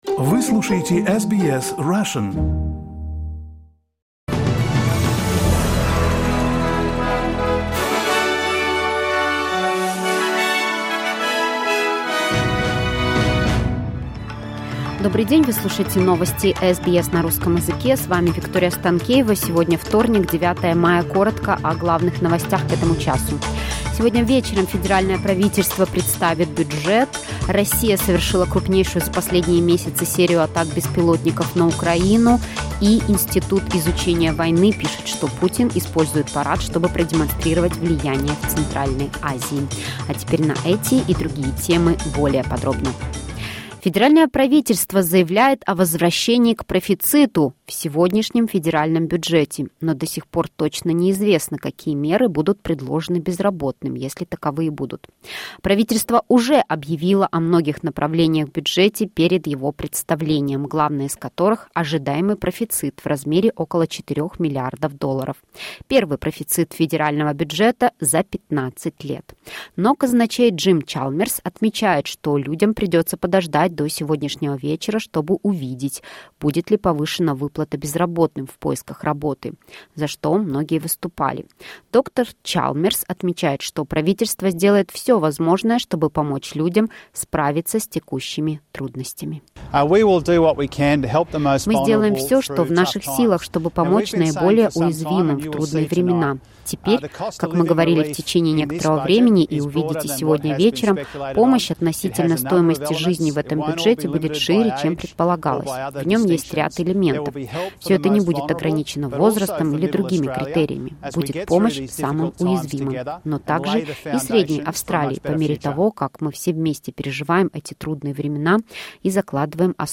SBS news in Russian — 09.05.2023